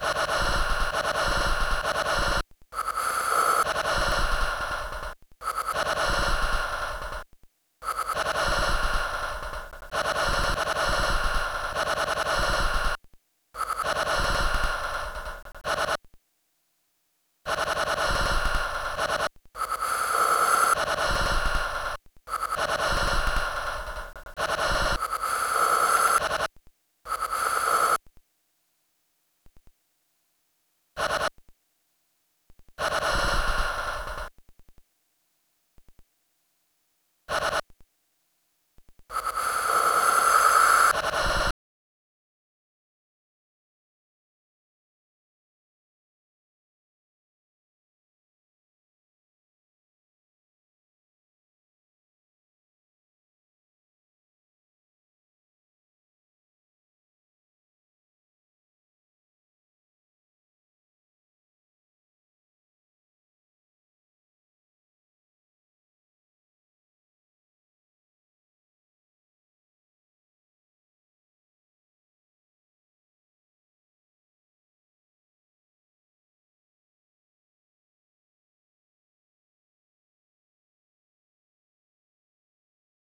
der titel: transit, legt eine suggestive bedeutungsspur, der das klangereignis auch folgen sollte. ein schönes ergebnis im detail, bei dem aber steigerungsmöglichkeiten warten. dies betrifft den äusseren aufbau, den ablauf des stücks. ein weiterer versuch mit den hier vorgestellten klangelementen, könnte zu jenem zwingenden ergebnis führen, den der titel ankündigt.